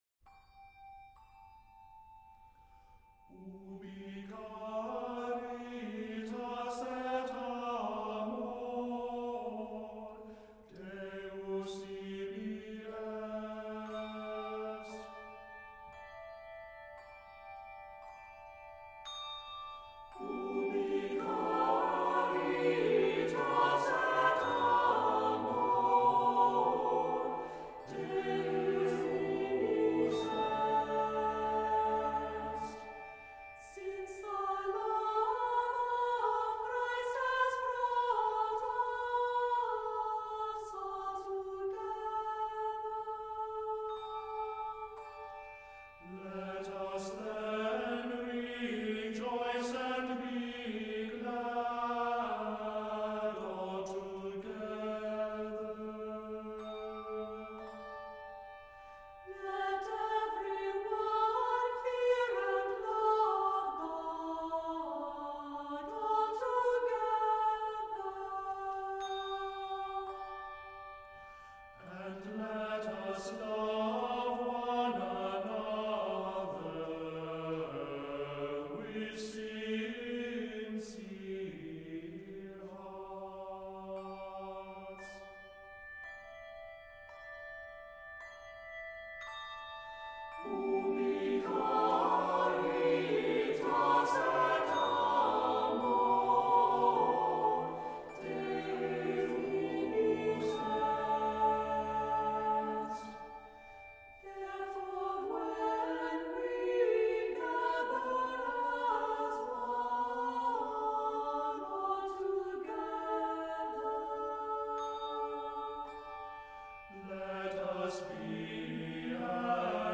Accompaniment:      A Cappella, Handbells
Music Category:      Christian